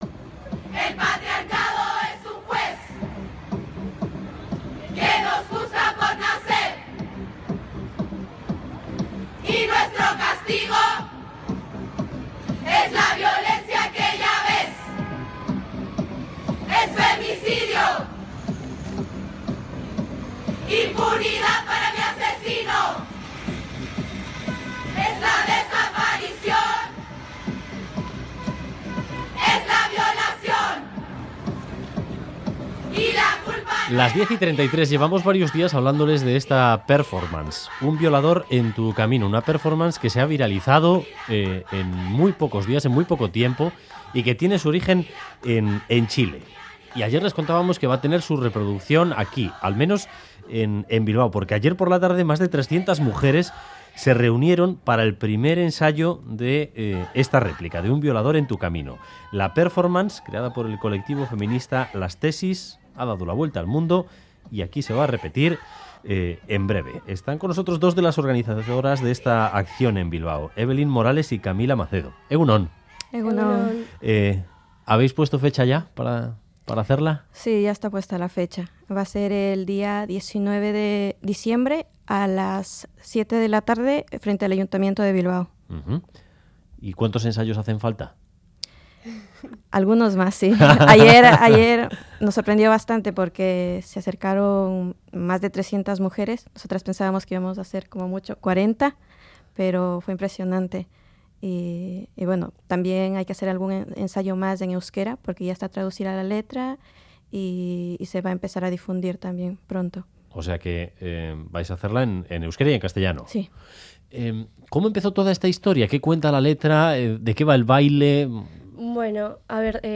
Audio: Más de 300 mujeres se reunieron en Bilbao para el primer ensayo de la réplica de 'Un violador en tu camino'. Hablamos con dos de las organizadoras.